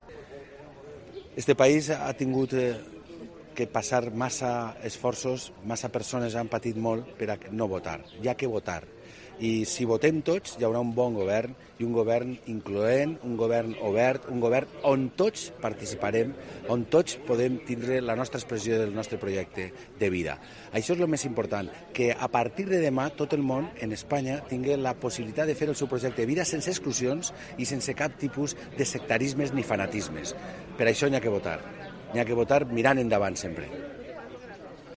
"Eso es lo más importante, que a partir de mañana todo el mundo en España tenga la posibilidad de hacer su proyecto de vida sin exclusiones y sin ningún tipo de sectarismo ni fanatismo", ha afirmado Puig tras votar en la Oficina de Turismo de Morella.